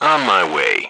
1 channel
H_soldier2_02.wav